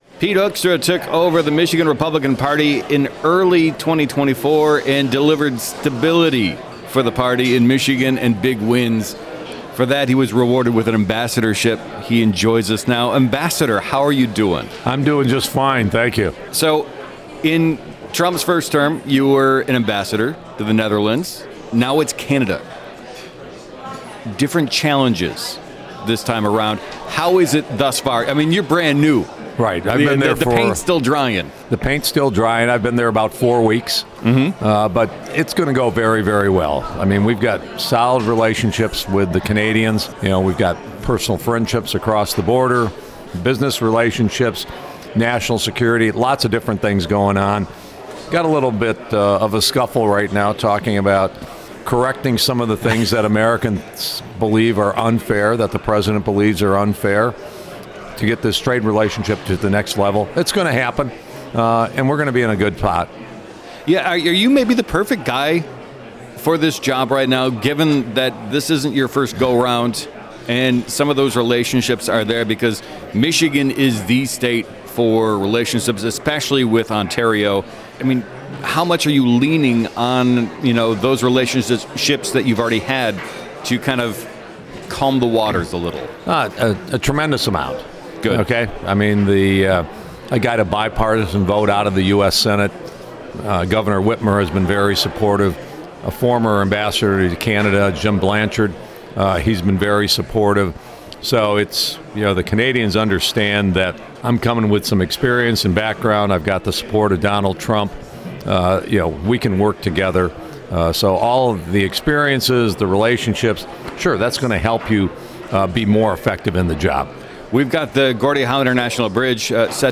In an interview with WDET during the 2025 Mackinac Policy Conference, Hoekstra said the economy is strong, and that worries about tariffs and a trade war with Canada are overblown.